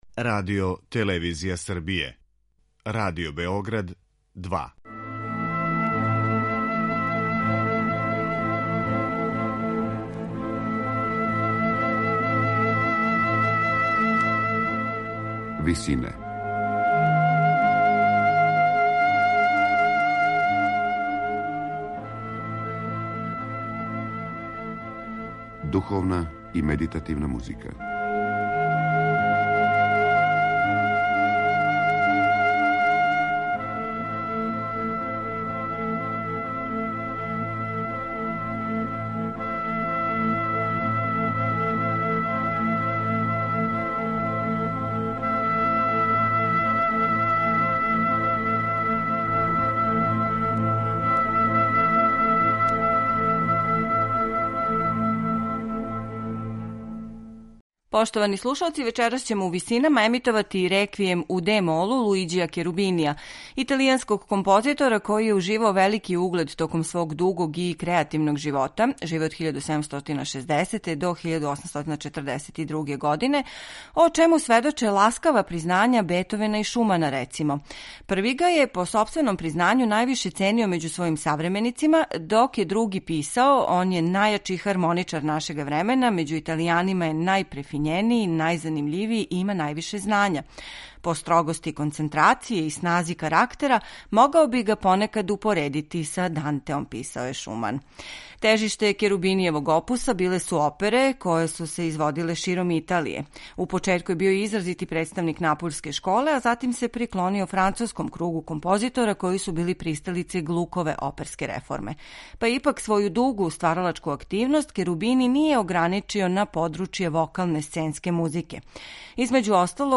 Снимак Хора и оркестра Чешке филхармоније којима диригује Игор Маркевич.